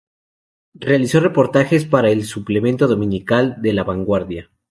do‧mi‧ni‧cal
/dominiˈkal/